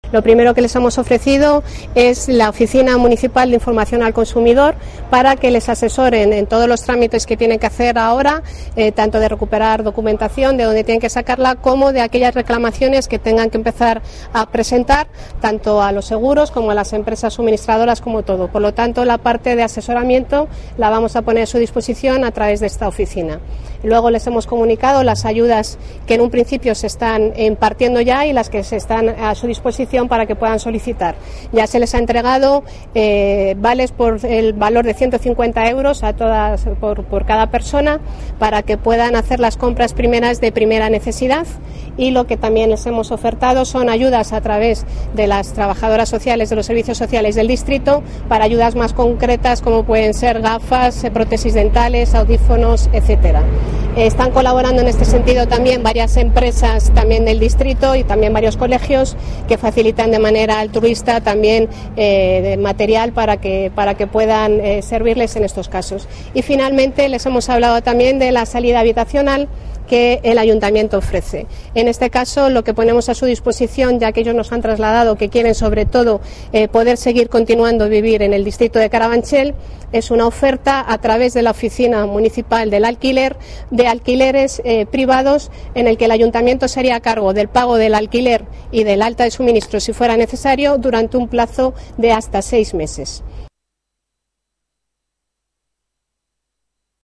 Nueva ventana:Declaraciones de la concejala de Carabanchel, Esther Gómez